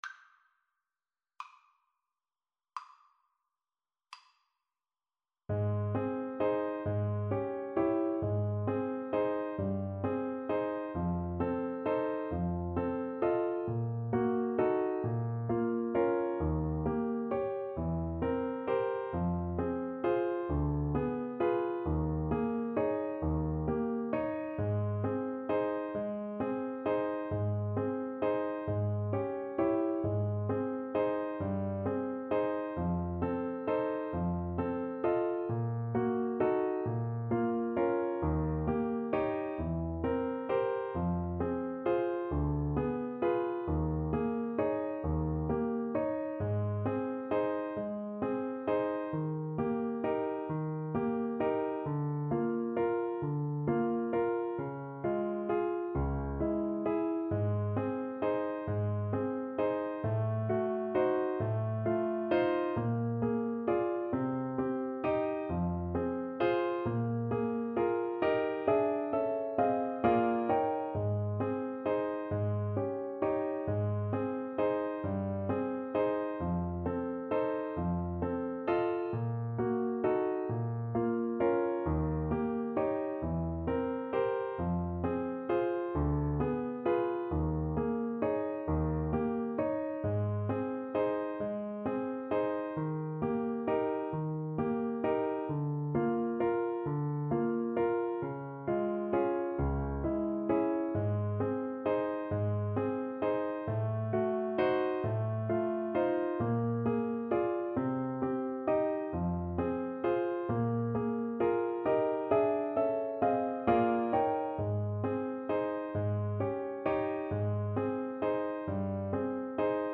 • Unlimited playalong tracks
Andante . = 44
Classical (View more Classical Viola Music)